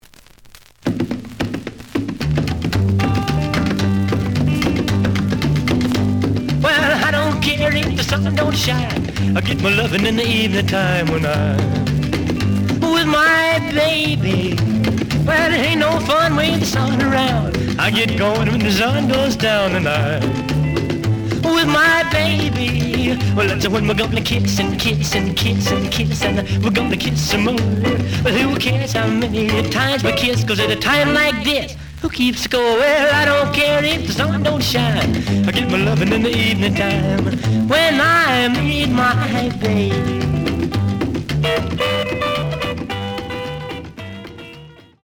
The audio sample is recorded from the actual item.
●Genre: Rhythm And Blues / Rock 'n' Roll
Looks good, but slight noise on both sides.)